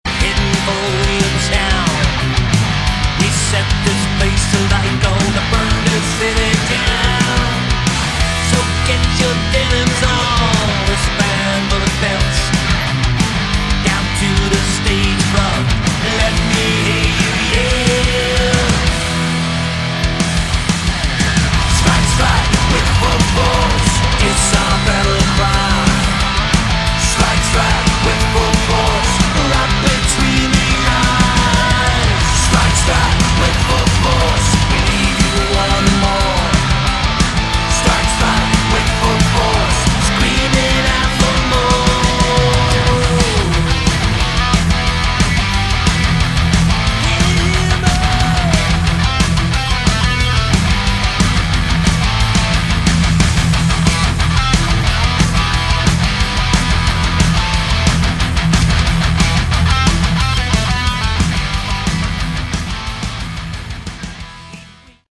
Category: Melodic Metal
vocals
bass